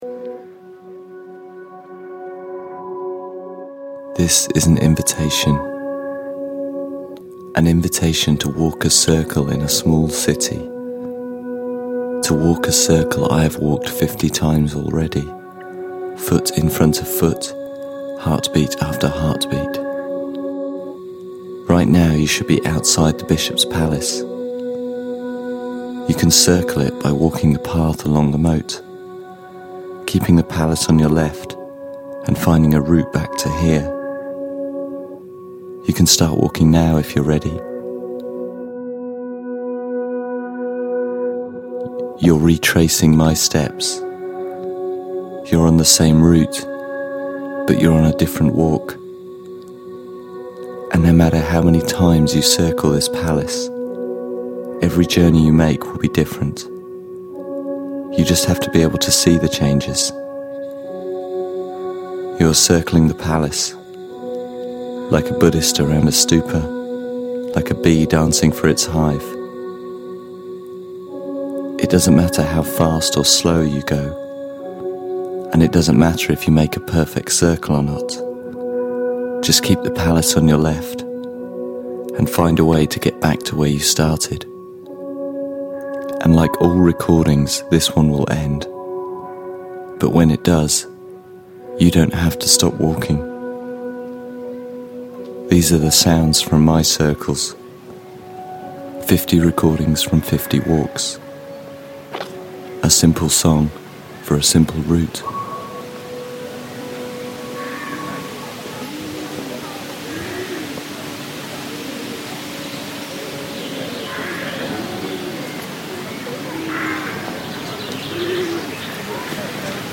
soundwalk composition, 'Song for Fifty Hearts'